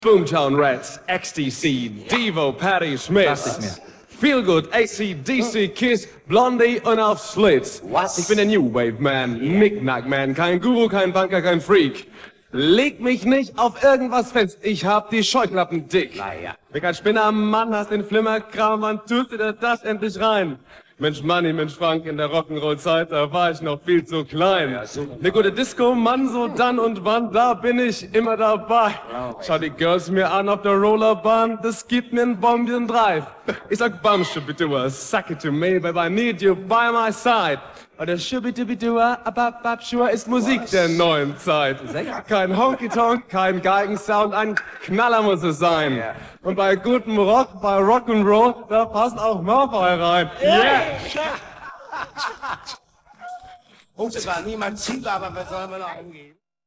Parte vocal